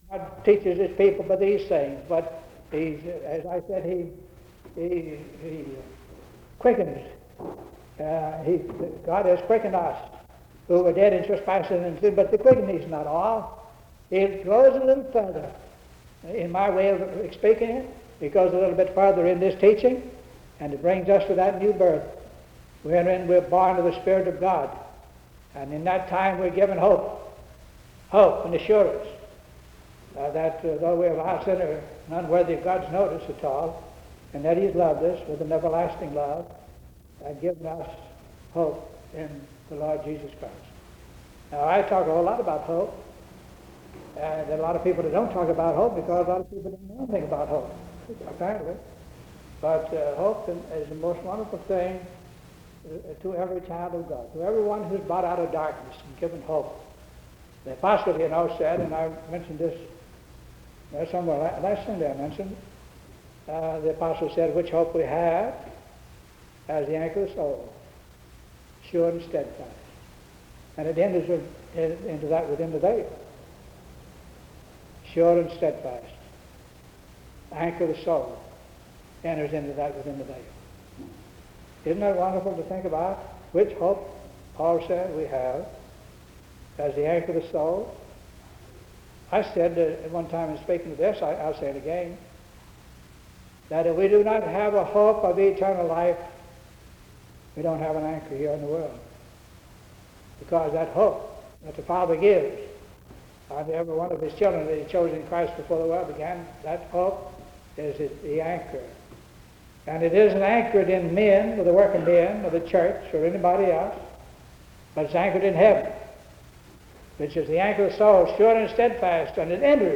Reversed Section of Sermon
• Corrected section of reversed recording that seems to be from a sermon, might be an accidental over-recording